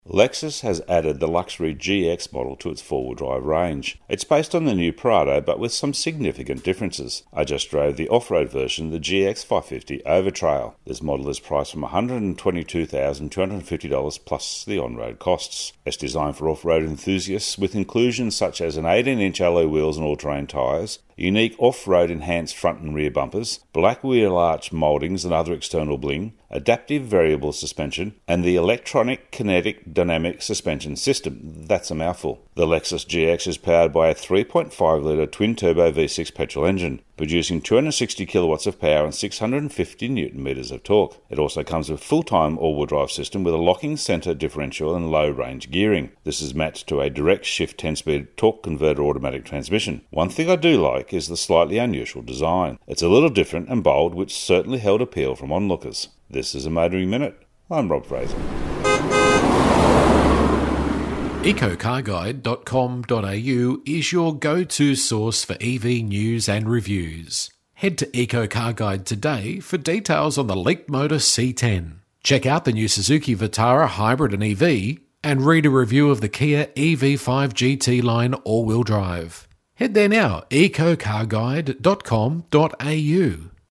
Lexus GX 550 Overtrail road test review